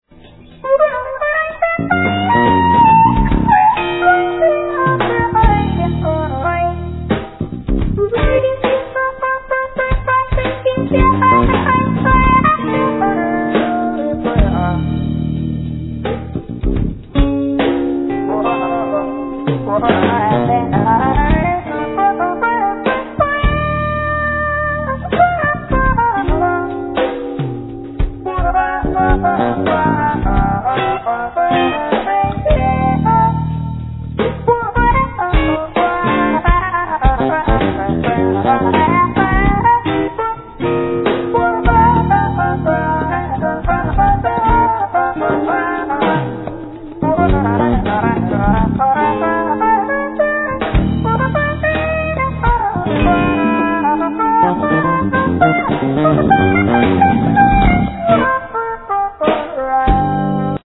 Drums
Bass
Alt sax
Percussions
Guitar, Effects